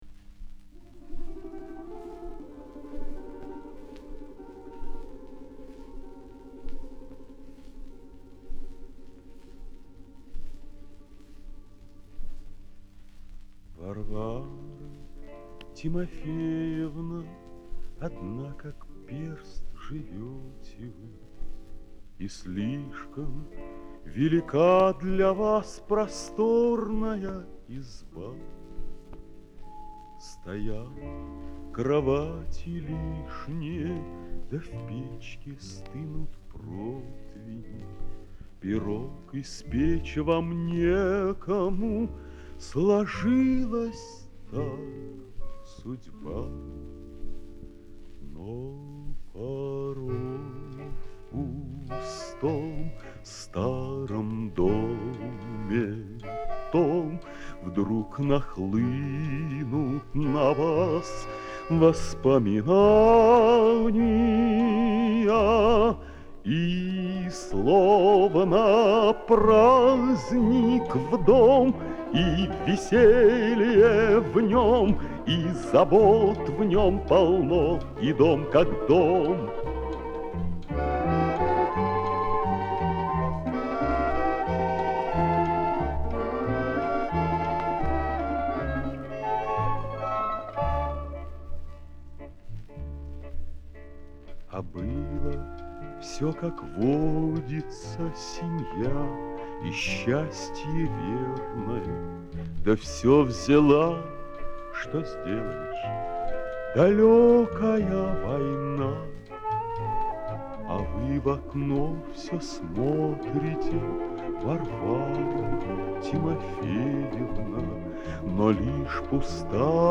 Оцифровка гибкой пластинки 2649.